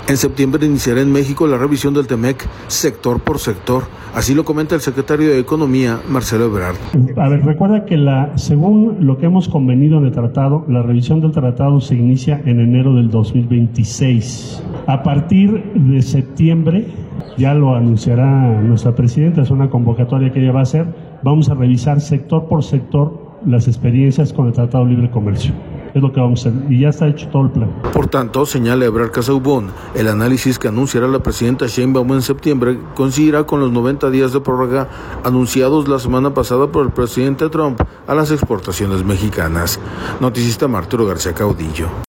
En septiembre iniciará en México la revisión del TMEC, sector por sector, así lo comenta el secretario de Economía, Marcelo Ebrard.